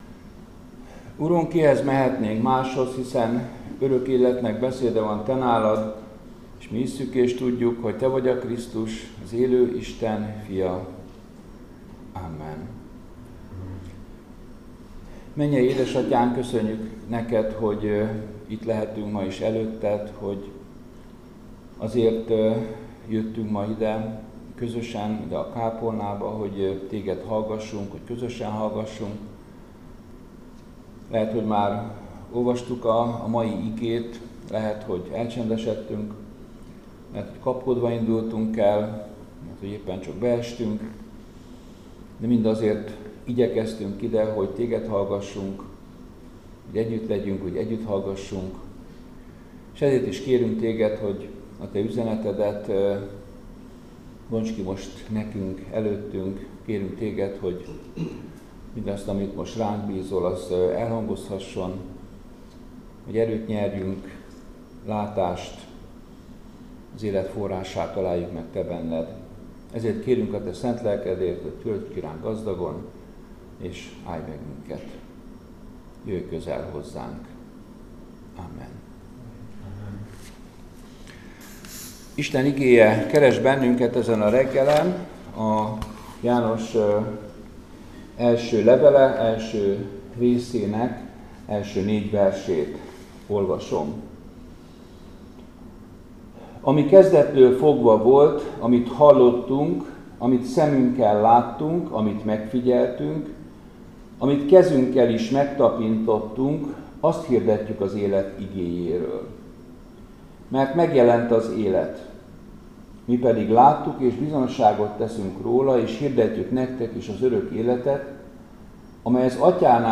Áhítat, 2024. április 23.